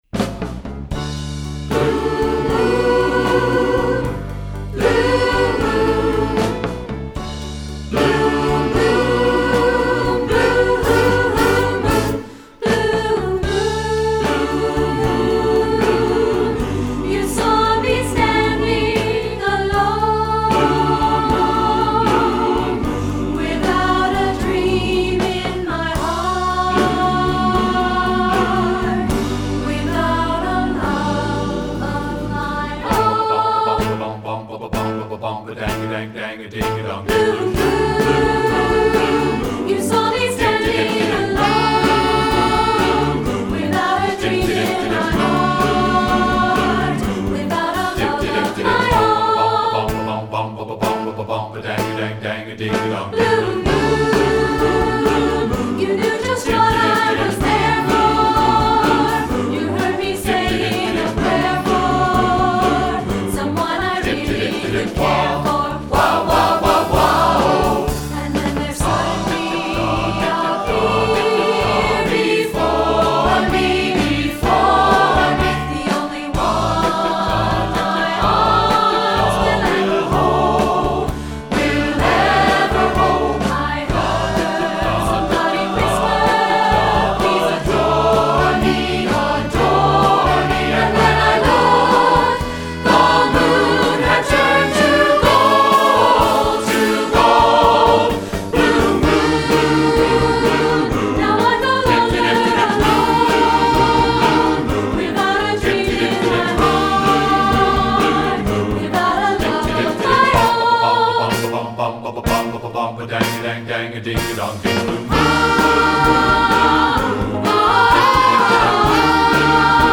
E-flat alto saxophone part:
B-flat tenor saxophone part:
Guitar part:
String bass part:
Drums part: